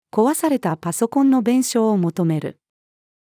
壊されたパソコンの弁償を求める。-female.mp3